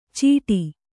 ♪ cīṭi